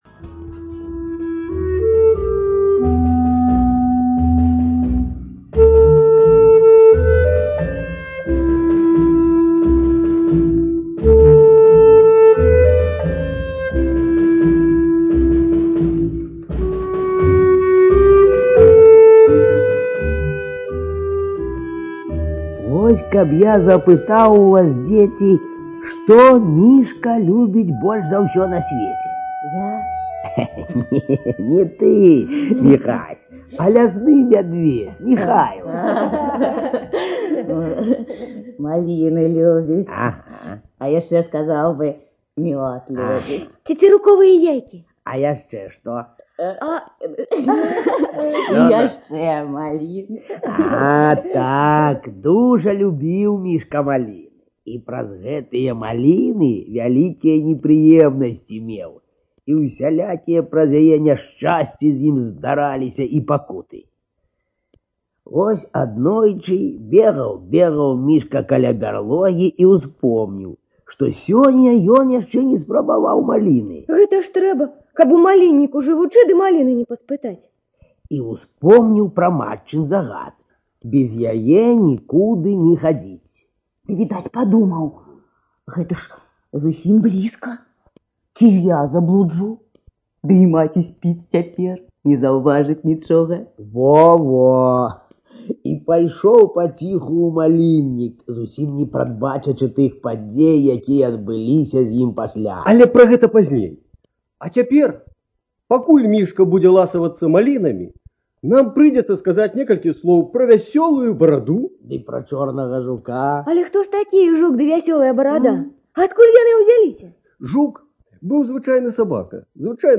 ЖанрРадиоспектакли на белорусском языке